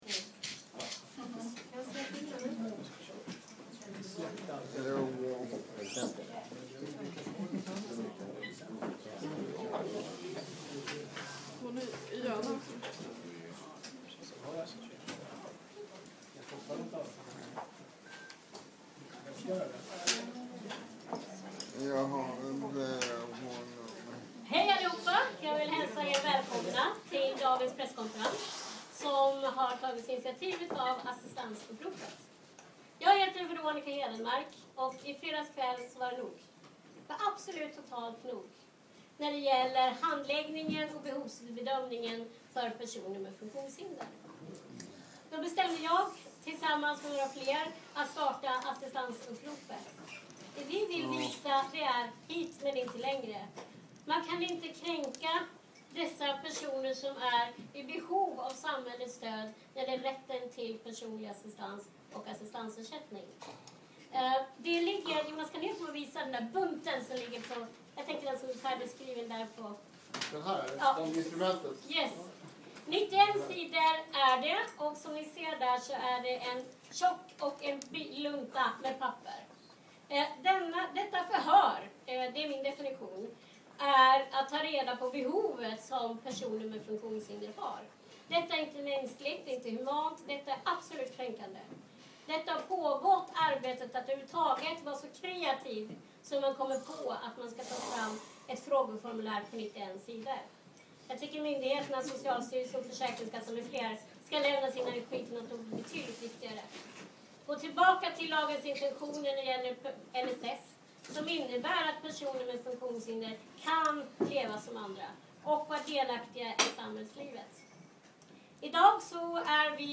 01_Presskonferens.mp3